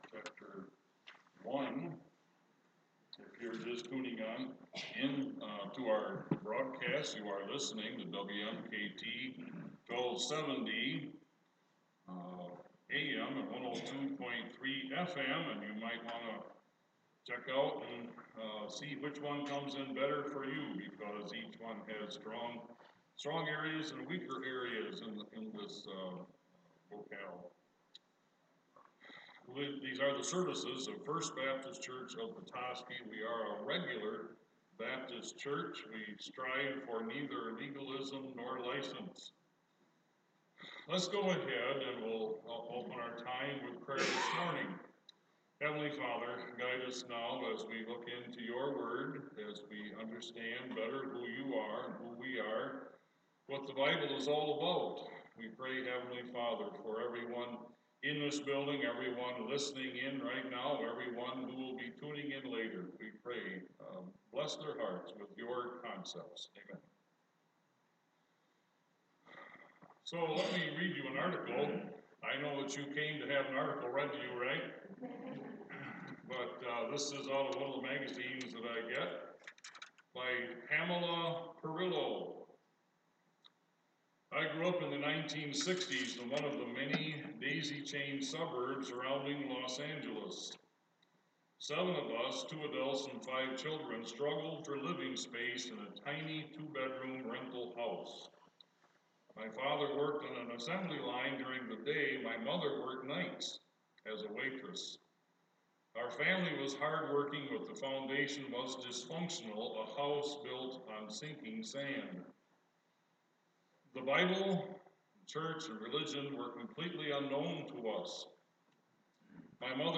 2 Peter | First Baptist Church of Petoskey Sunday Morning Bible Teaching